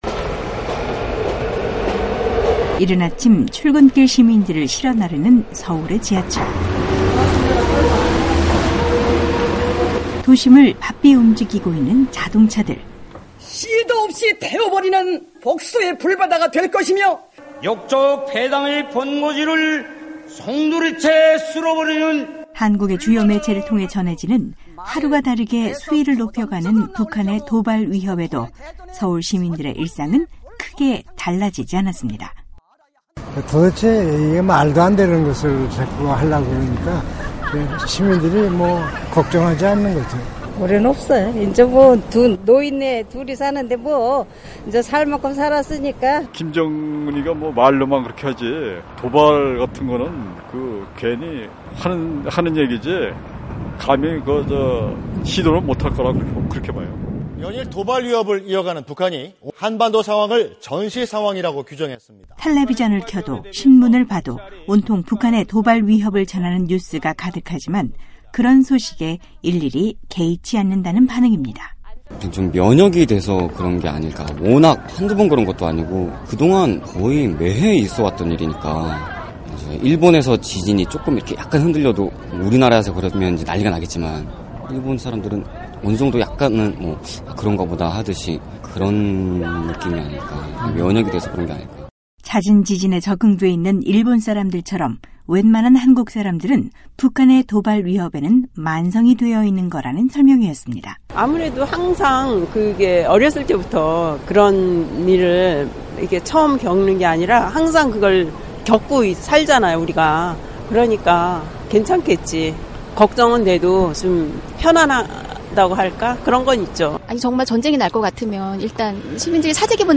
오늘은 북한의 계속되는 도발에도 비교적 차분한 분위기가 유지되고 있는 서울시민들의 목소리를 들어보겠습니다. 전쟁은 일어나지 않아야 하며, 막연한 불안감 보다는 의연하게 대처하는 것이 가장 현명한 방법이라는 반응이었습니다.